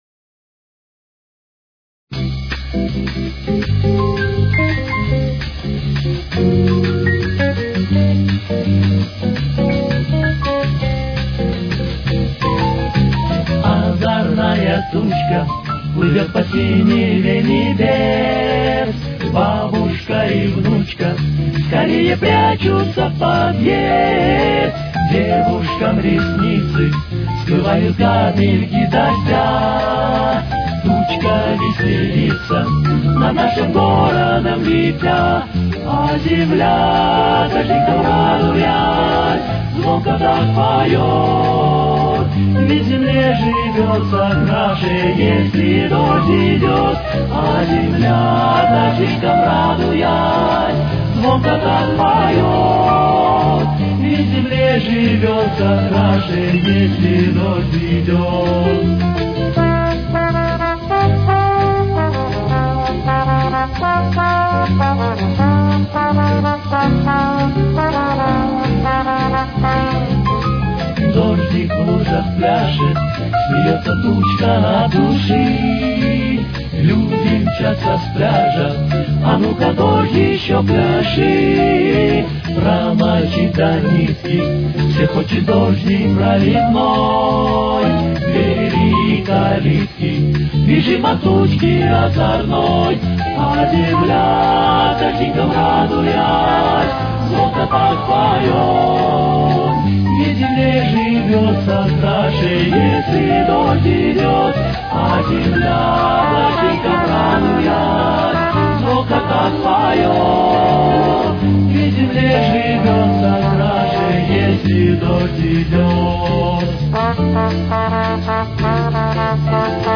с очень низким качеством (16 – 32 кБит/с)
Тональность: Фа мажор. Темп: 168.